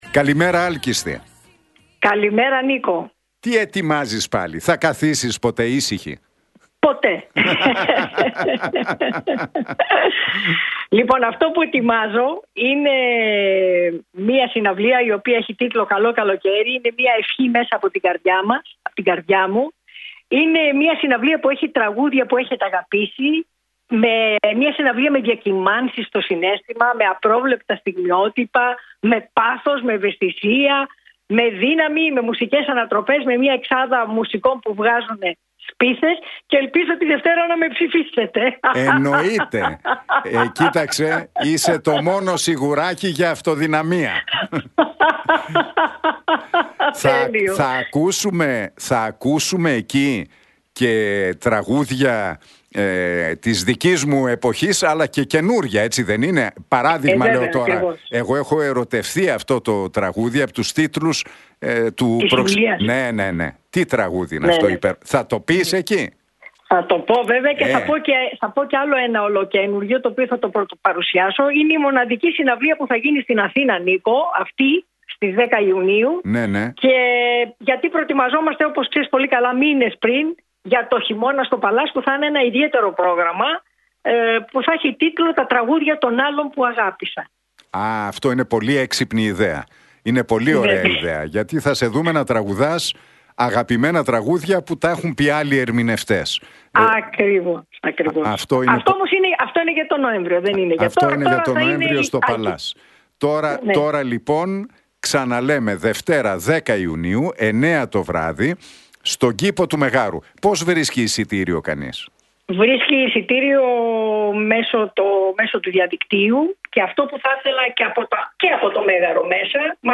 Για τη συναυλία με τίτλο «Καλό Καλοκαίρι» που θα πραγματοποιηθεί τη Δευτέρα 10 Ιουνίου στις 9 το βράδυ στον Κήπο του Μεγάρου μίλησε στον Realfm 97,8 και την εκπομπή του Νίκου Χατζηνικολάου, η Άλκηστις Πρωτοψάλτη.